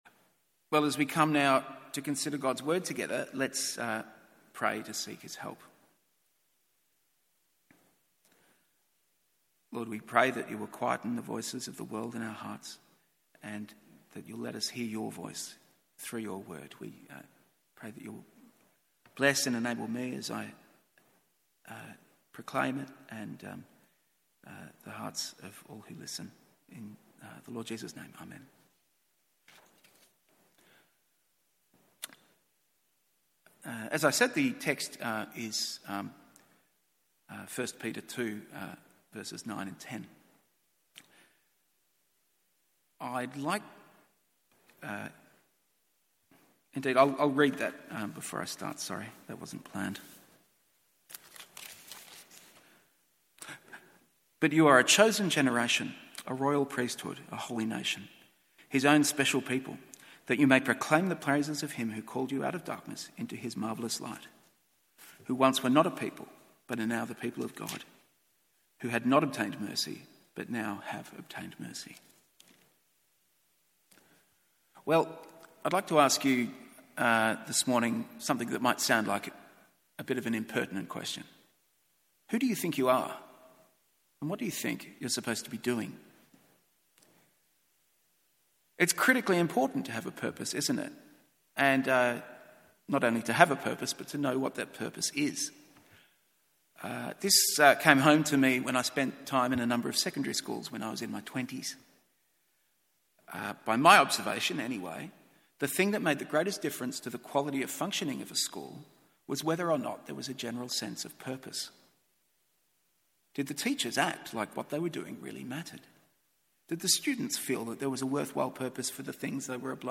MORNING SERVICE 1 Peter 2:9-10…